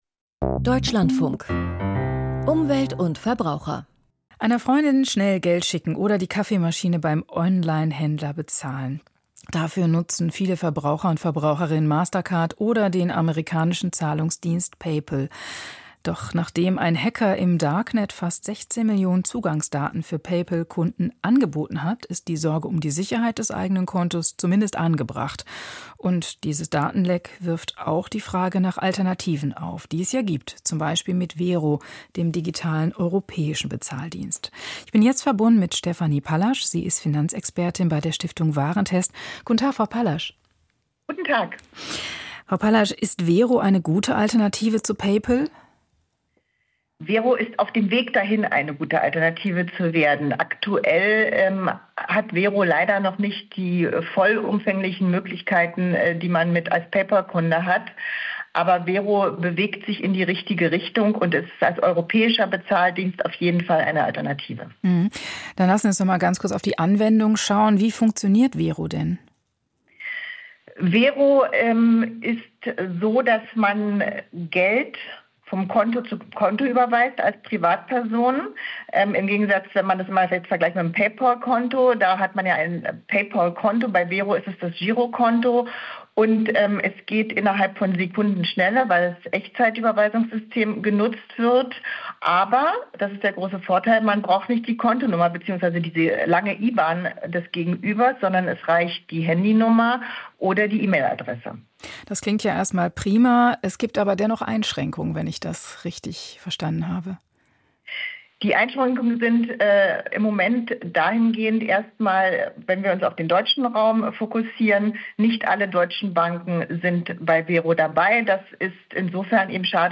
Europäischer Zahlungsdienst Wero - Interview